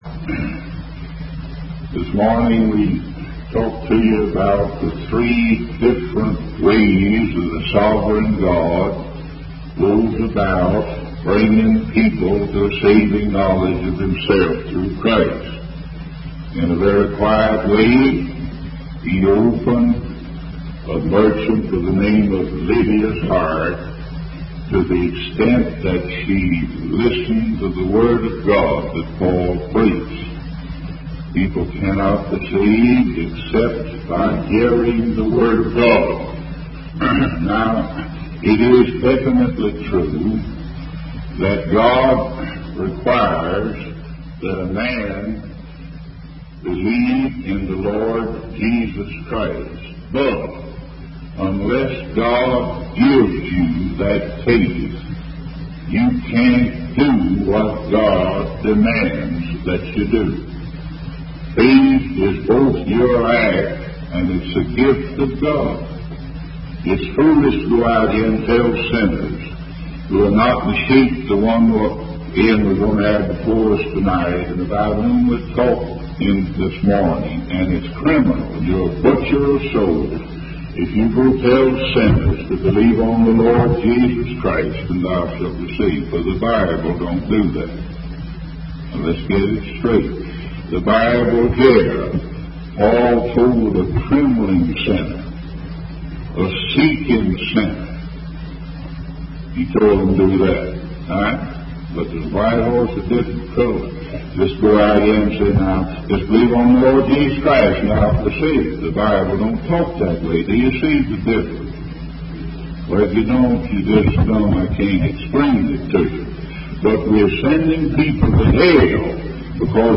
In this sermon, the preacher tells a story about a man named Sandworld who is seeking salvation.